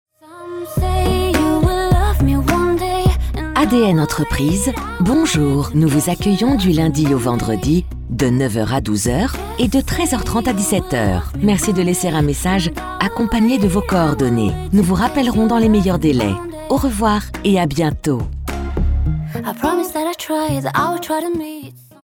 Votre texte d’accueil sera quant à lui prononcé par un vrai comédien (homme ou femme selon votre choix) et personnalisé avec votre nom ainsi que celui de votre entreprise, dans la langue que vous souhaitez.
Pour un authentique design musical, vos messages vocaux sont enregistrés dans un vrai studio d’enregistrement et mixés par une équipe de professionnels du son (directeur artistique, compositeurs, ingénieurs du son).
• Messagerie vocale personnalisée téléphonie fixe
adn-entreprise_demo-repondeur-ouvert.mp3